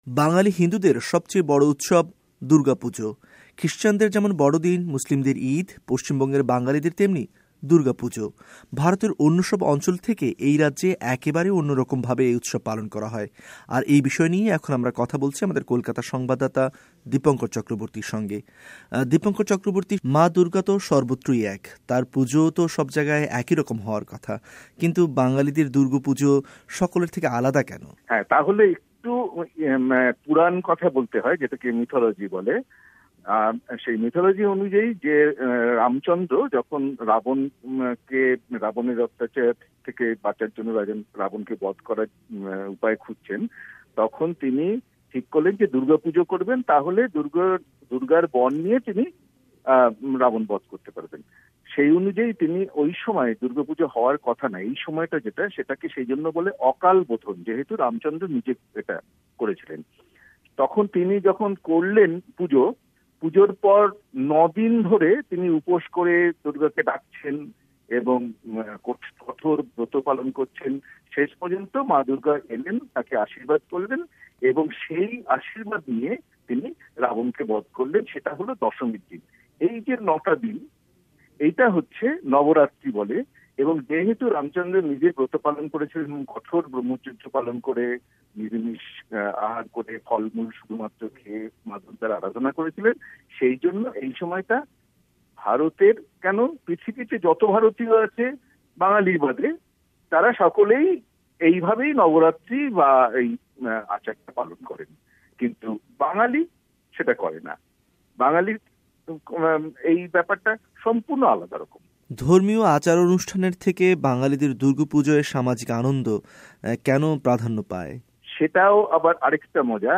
আগে যে ভাবে দুর্গাপুজো হতো, এখন কি তা বদলে গিয়েছে? পশ্চিমবঙ্গের মুখ্যমন্ত্রী মমতা বন্দ্যোপাধ্যায় দুর্গাপূজোয় এত উৎসাহীত কেন-- এমন নানা প্রশ্নের উত্তর জানিয়েছেন আমাদের সংবাদদাতা।